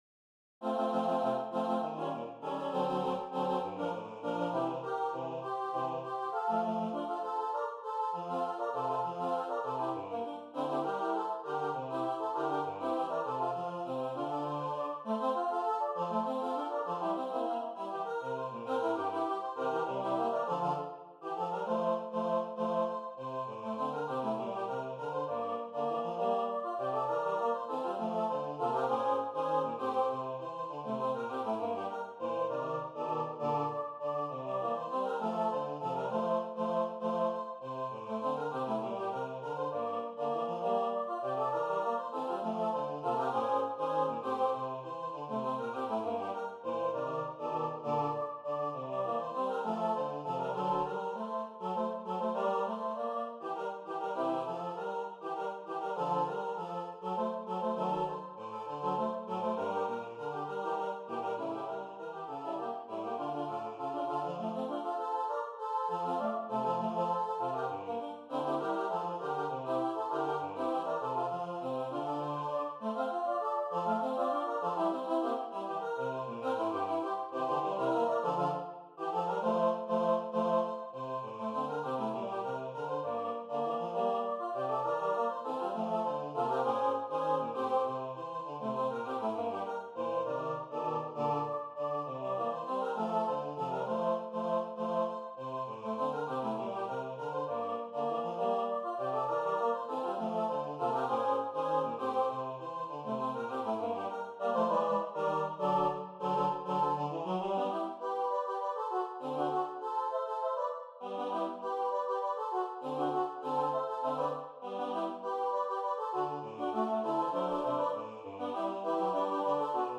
Porro
arranged for choir
arregladas para coro